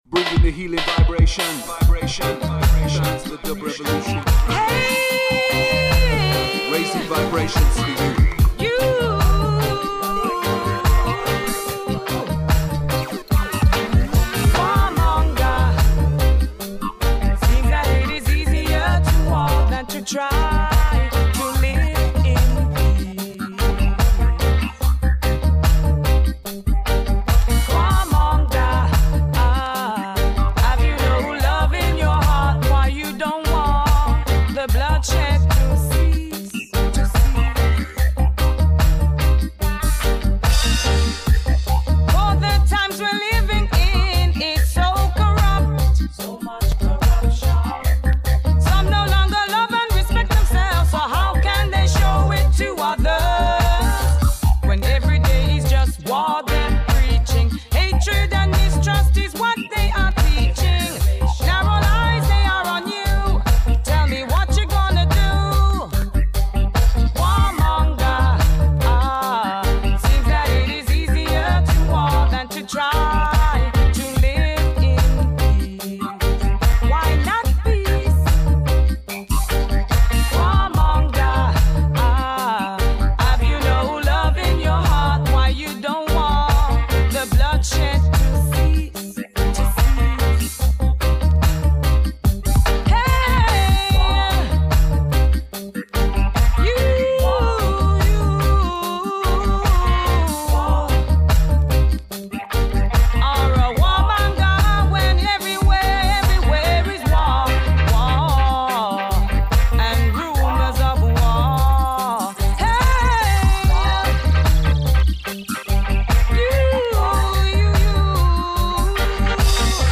modern roots reggae selection
melodica
Violin
Accordian
Trumpet
percussion